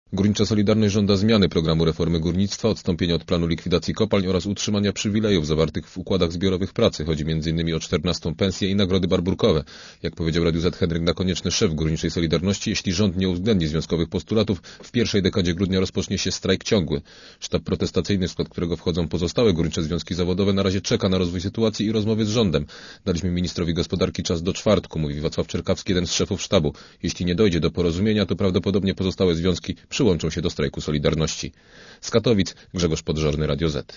Posłuchaj relacji reportera Radia Zet (136K)